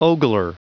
Prononciation du mot ogler en anglais (fichier audio)
Prononciation du mot : ogler